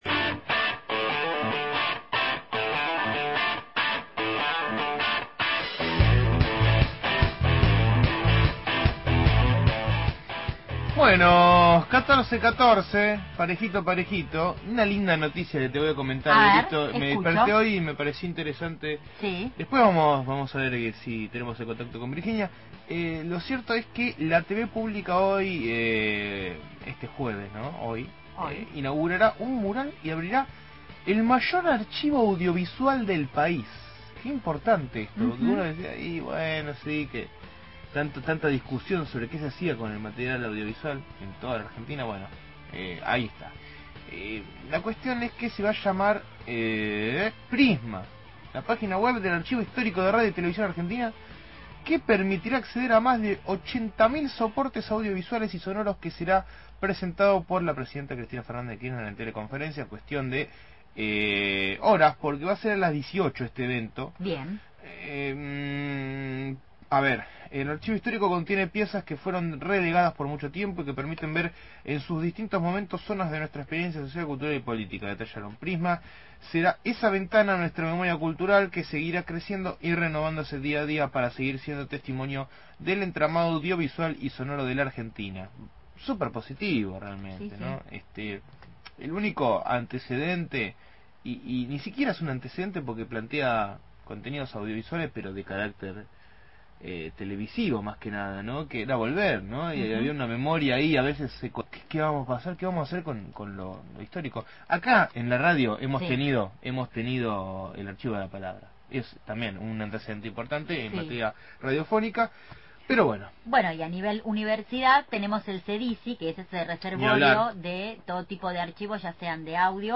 MÓVIL/ Comenzaron las 2° Jornadas de Salud Mental – Radio Universidad